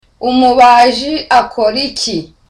Dialogue: A conversation between Tereza and Sonita
(Curious)